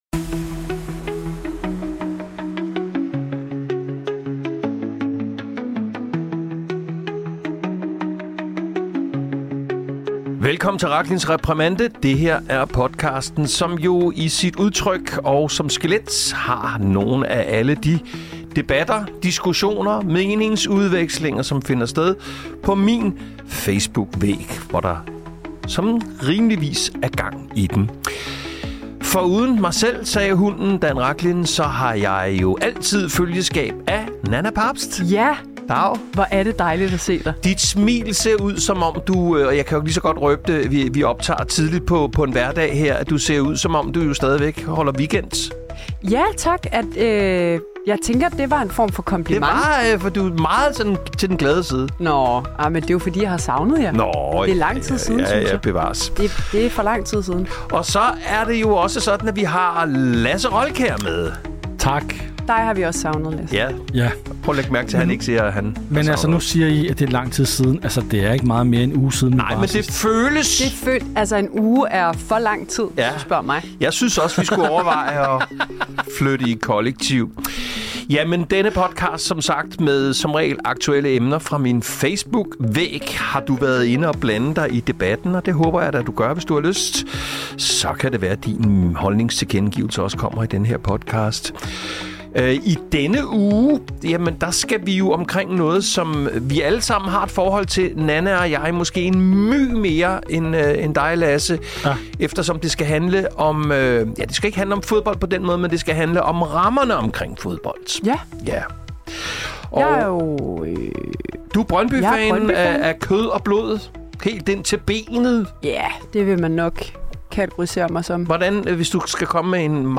Panelet læser de skarpeste kommentarer højt—både ros, raserier og de vigtige nuancer—så du får det fulde billede af, hvad der faktisk splitter (og samler) fansene.